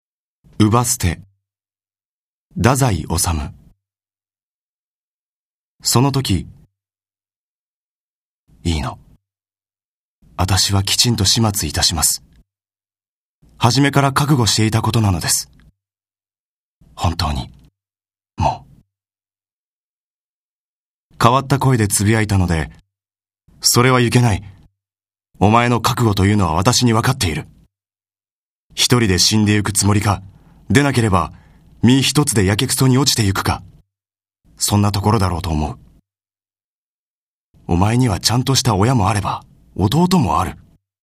朗読ＣＤ　朗読街道135「姥捨・作家の像」太宰治
朗読街道は作品の価値を損なうことなくノーカットで朗読しています。